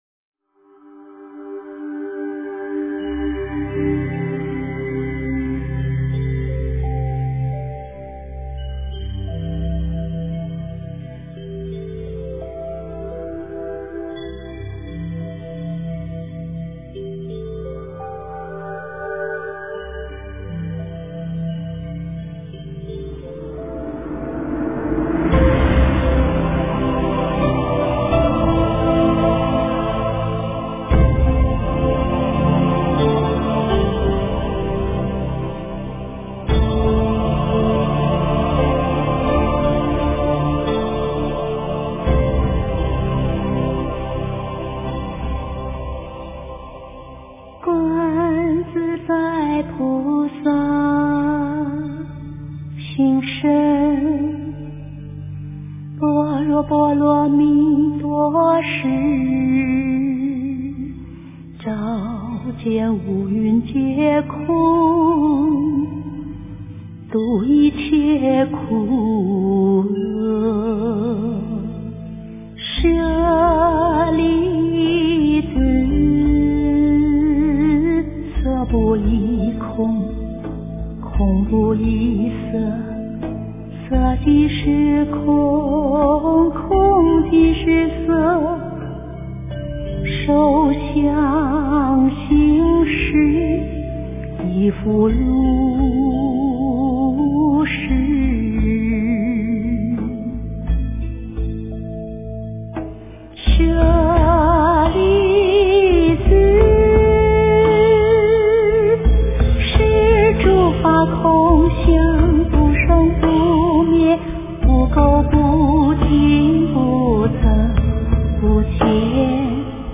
诵经
佛音 诵经 佛教音乐 返回列表 上一篇： 大悲咒 下一篇： 般若波罗蜜多心经-梵唱 相关文章 信心一炷香--觉慧法音合唱团 信心一炷香--觉慧法音合唱团...